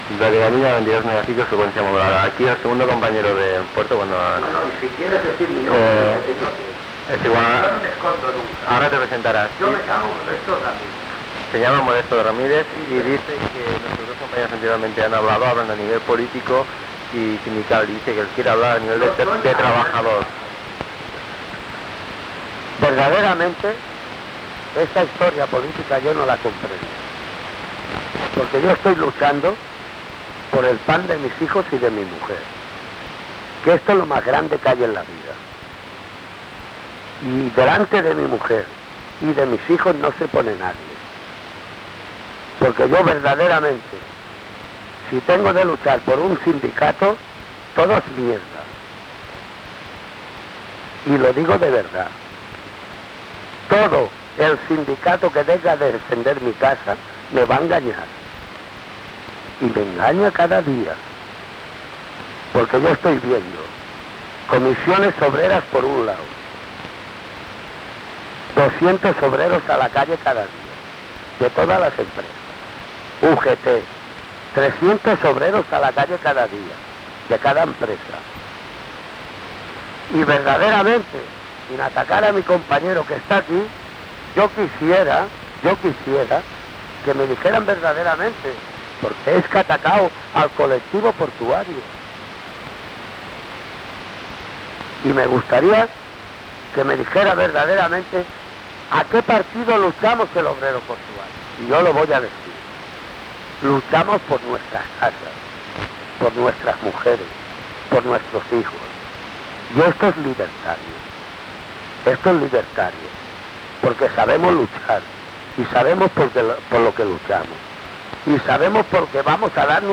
dacf8317fcc97fa8bbb672a54737d54c109bdca6.mp3 Títol Ràdio Gavina Emissora Ràdio Gavina Titularitat Tercer sector Tercer sector Lliure Descripció Debat sobre eleccions sindicals: sí o no, amb la participació de dos treballadors del Port de Barcelona. Gènere radiofònic Informatiu